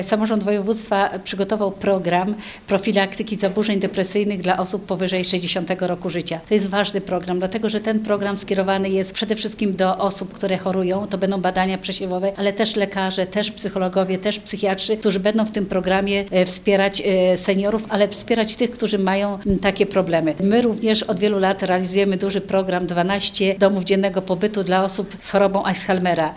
Zależy nam na stworzeniu jak najlepszych warunków do godnego życia i odpoczynku, aby seniorzy nie czuli się samotni i mogli aktywnie spędzać swój czas – mówi członkini zarządu województwa mazowieckiego – Elżbieta Lanc.